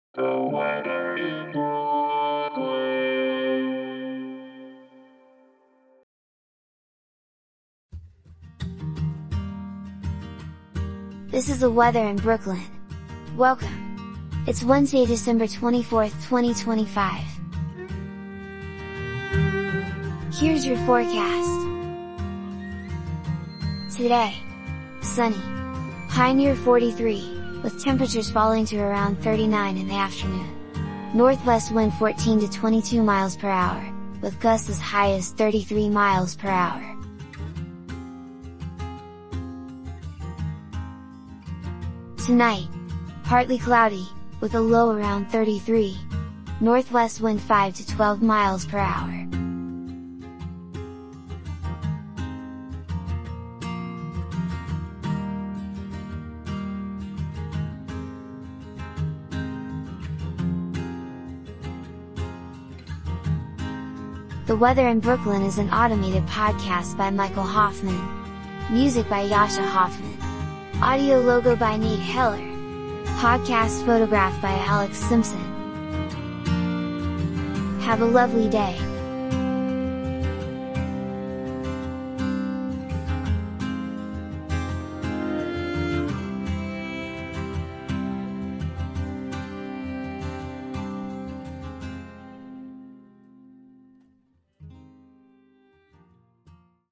An automated podcast bringing you your daily weather forecast for Brooklyn, NY.